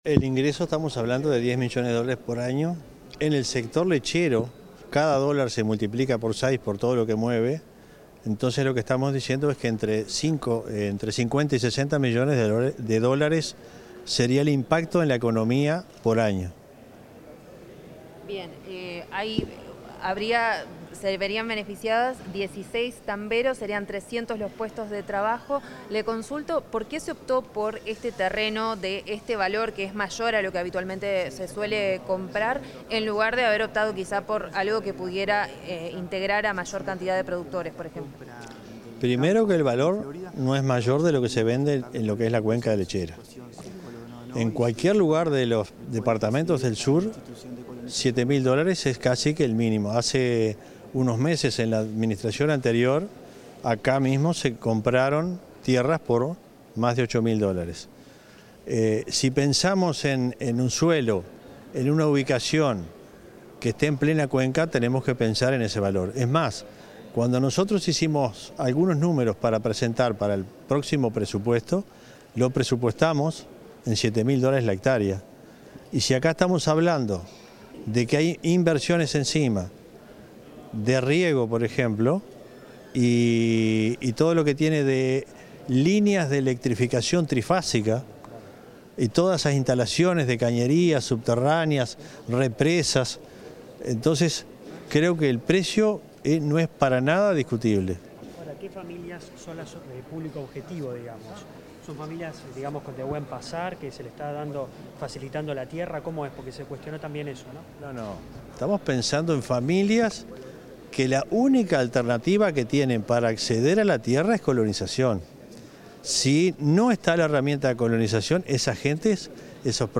Declaraciones a la prensa del presidente del Instituto de Colonización, Eduardo Viera
El presidente del Instituto Nacional de Colonización, Eduardo Viera, dialogó con la prensa luego de la conferencia sobre la adquisición de un inmueble